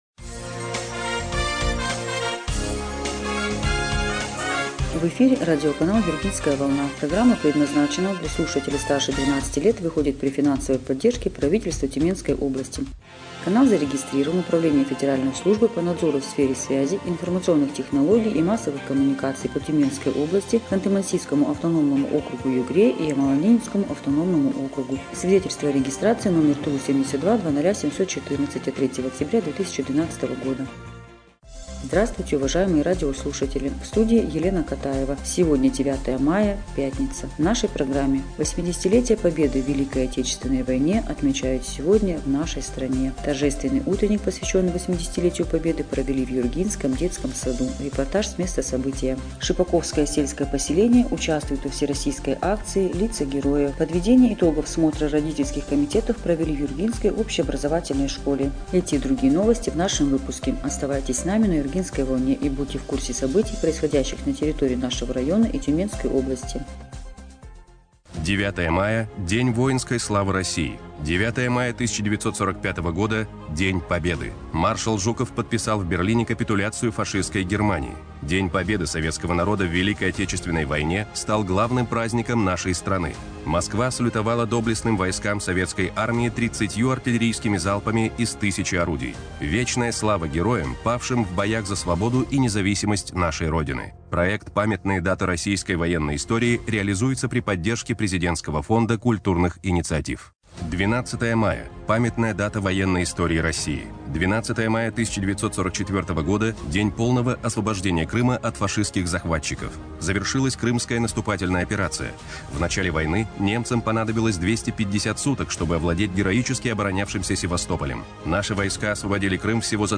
Эфир радиопрограммы "Юргинская волна" от 9 мая 2025 года
Репортаж с места события.